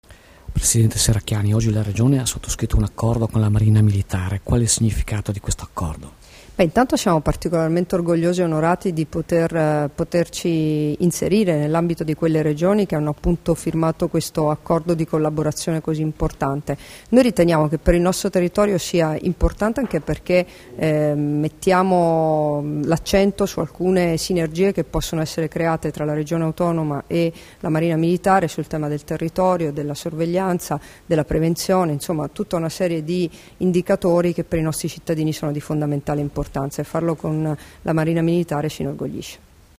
Dichiarazioni di Debora Serracchiani (Formato MP3) [637KB]
sull'Accordo tra Regione Friuli Venezia Giulia e Marina Militare Italiana, rilasciate a Trieste il 22 dicembre 2014